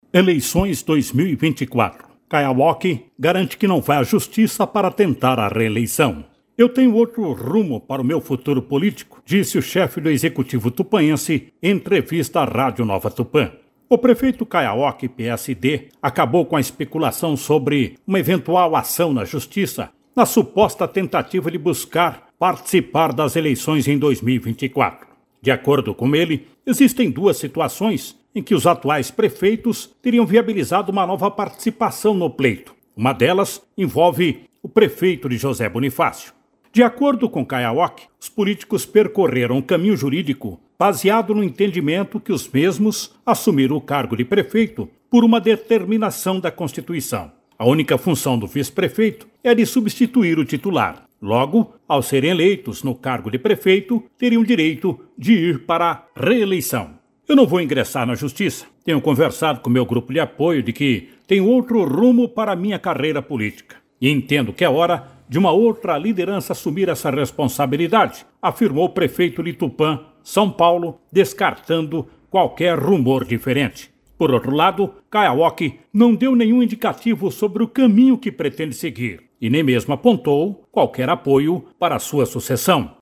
“Eu tenho outro rumo para meu futuro político”, disse o chefe do Executivo tupãense, em entrevista à Rádio Nova Tupã.
CAIO-AOQUI-RADIO.mp3